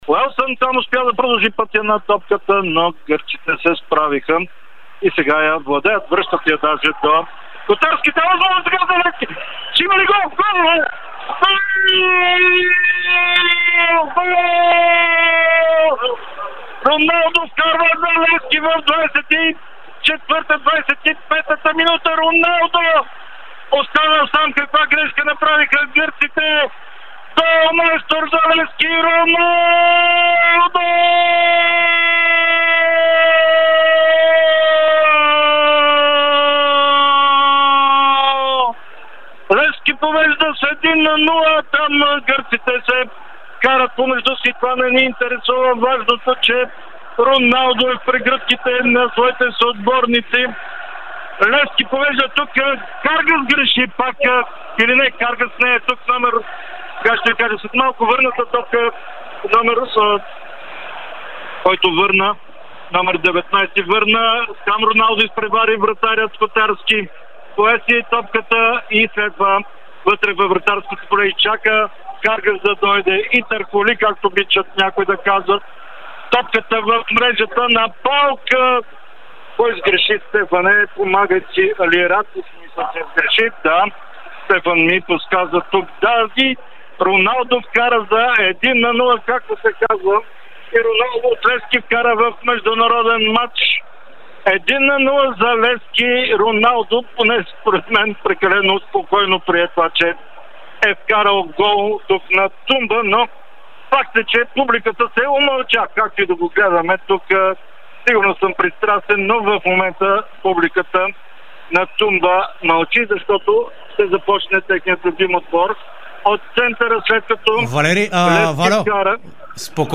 Експресивният коментар по Дарик радио на гола, смълчал ‘‘Тумба‘‘ (АУДИО)